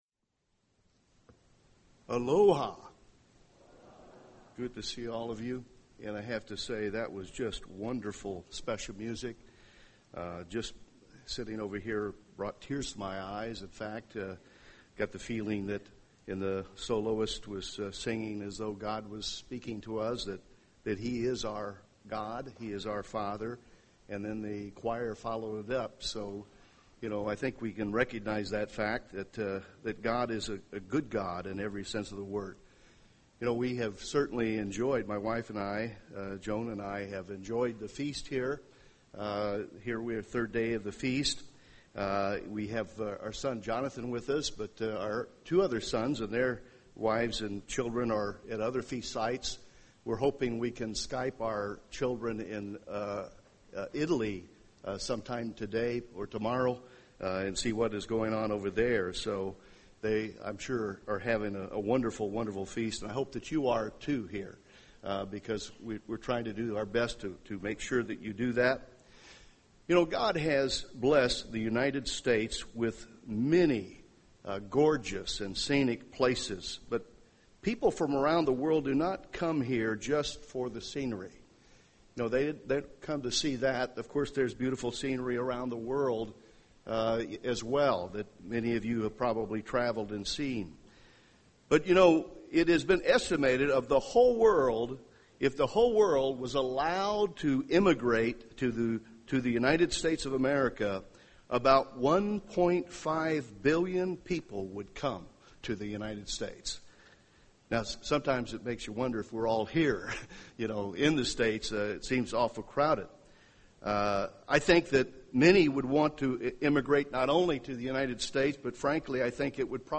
This sermon was given at the Maui, Hawaii 2011 Feast site.